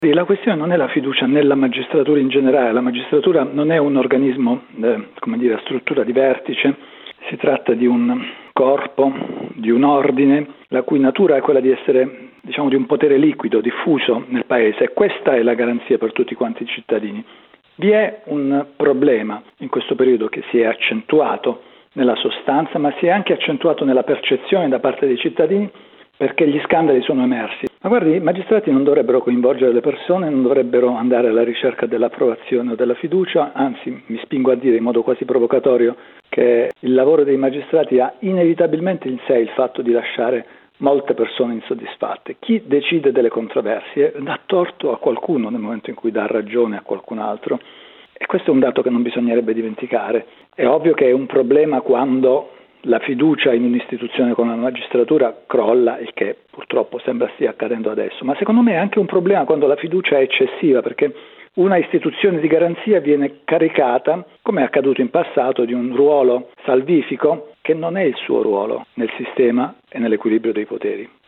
All’ex magistrato e scrittore Gianrico Carofiglio abbiamo chiesto se gli italiani hanno perso fiducia nei magistrati